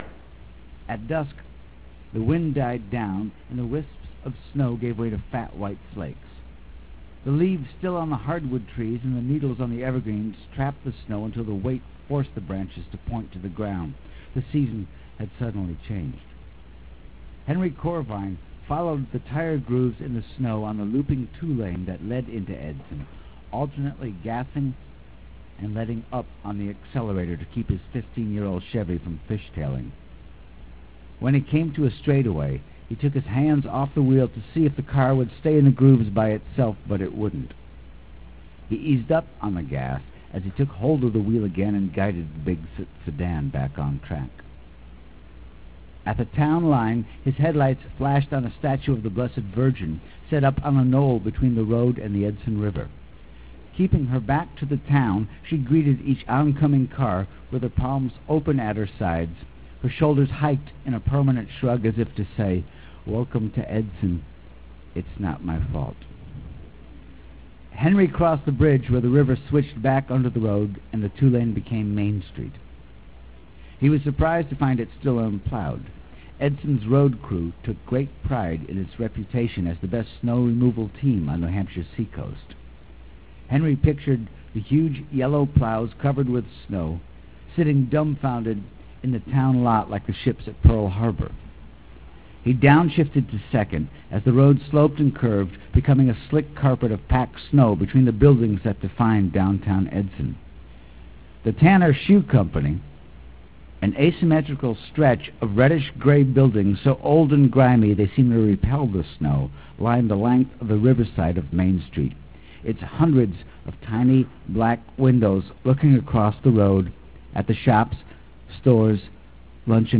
Reading (Audio File)
Reading (Audio File) Here is a sound file of Bill Morrissey reading the first chapter of Edson .